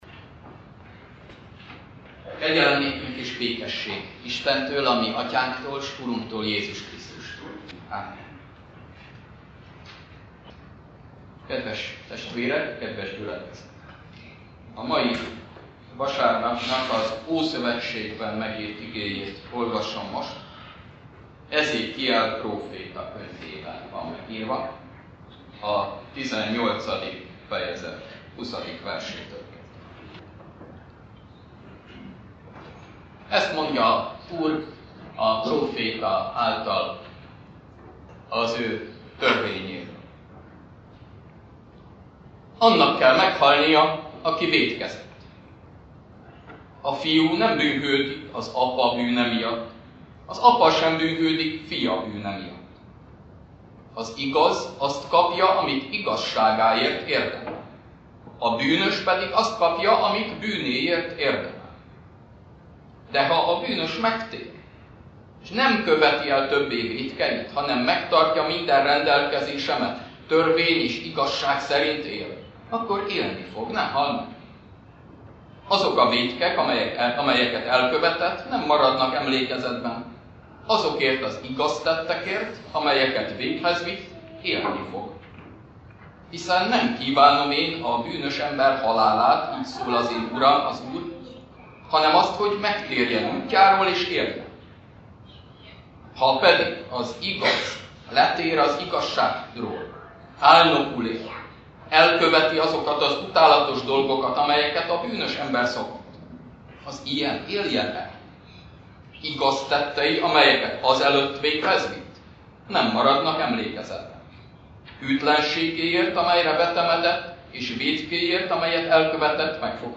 Konfirmációs jubileumi istentisztelet